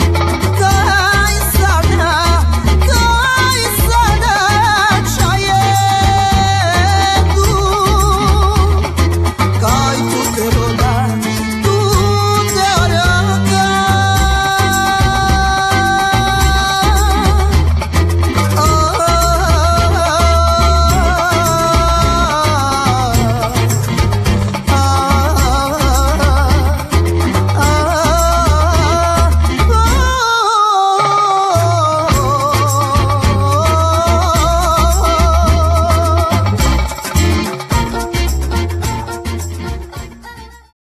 gitara guitar
akordeon accordion
skrzypce violin
kontrabas double bass
instr. perkusyjne percussions